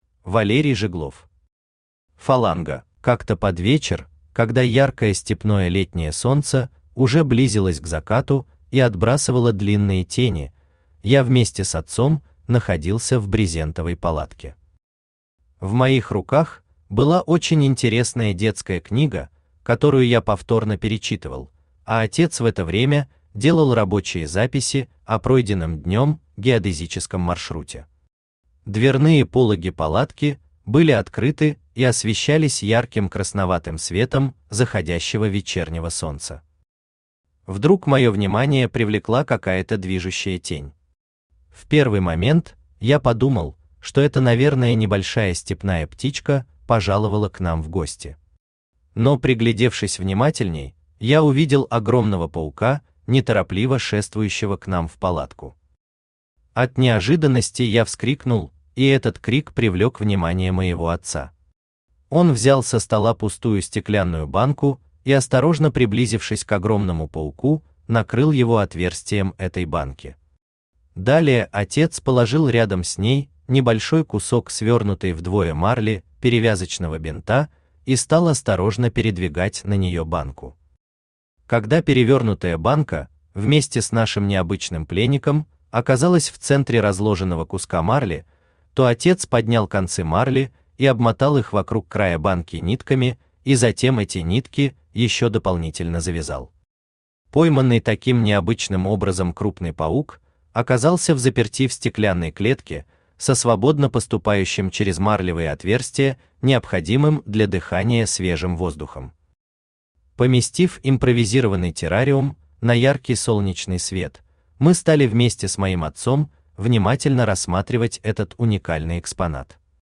Aудиокнига Фаланга Автор Валерий Жиглов Читает аудиокнигу Авточтец ЛитРес.